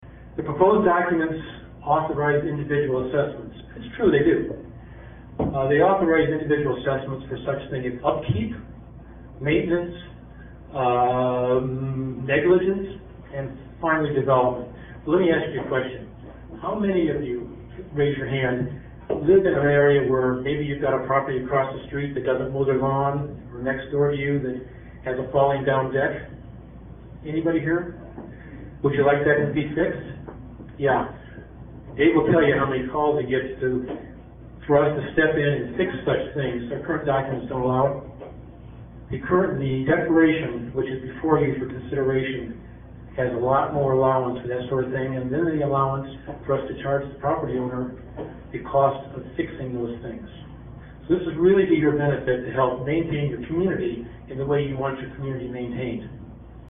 For those who weren’t able to attend the 5/20/05 meeting at Reynolds Fire Hall, we offer up another little gem – the devilish concept of Individual Assessments.